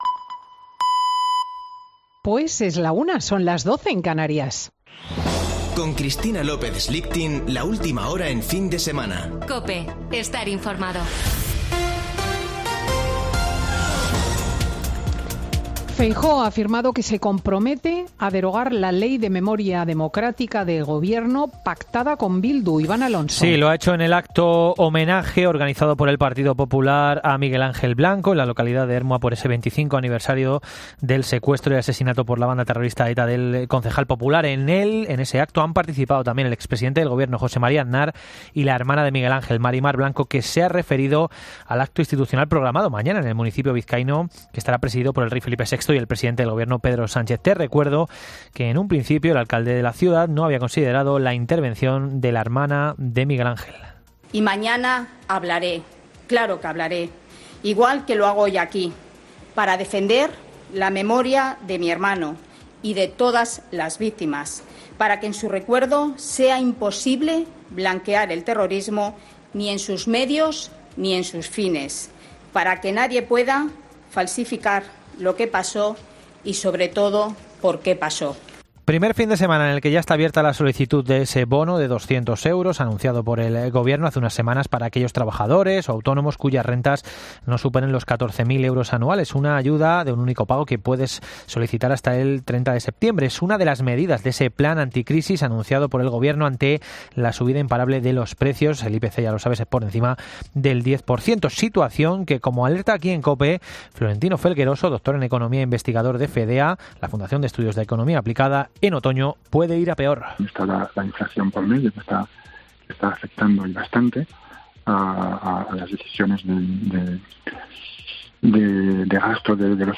Boletín de noticias de COPE del 9 de julio de 2022 a las 13.00 horas